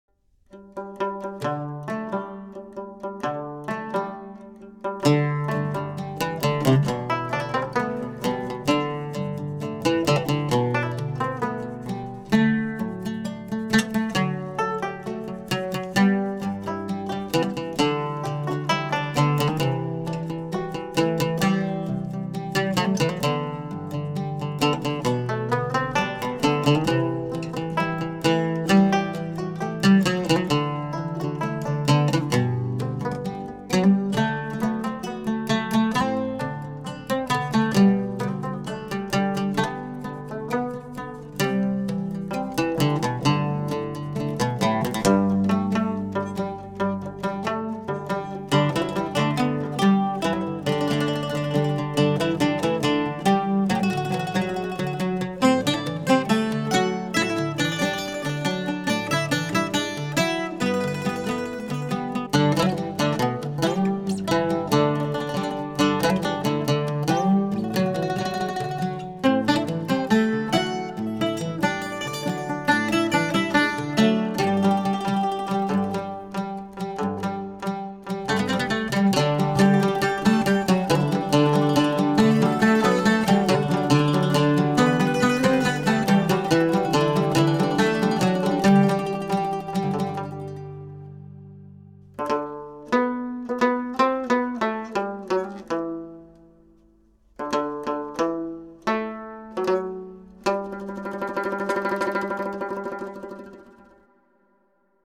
The group of three are sufi artists